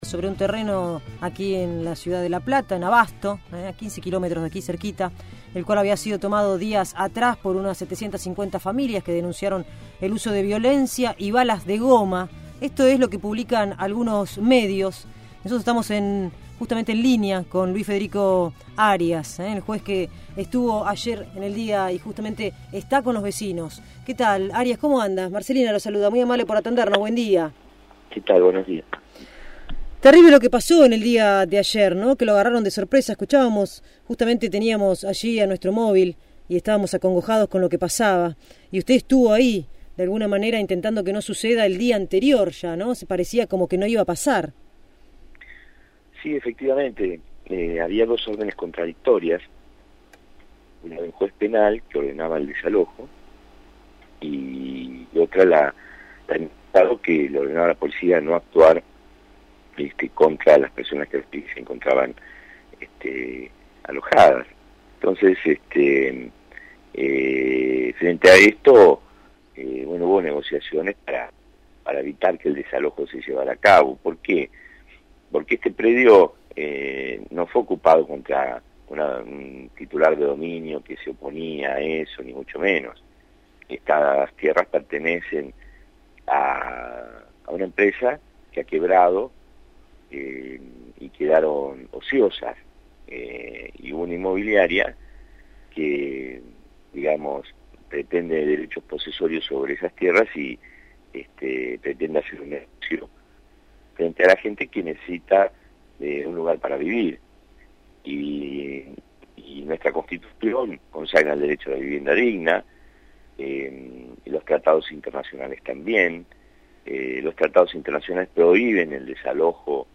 El titular del Juzgado en lo Contencioso Administrativo 1, Luis Federico Arias, dialogó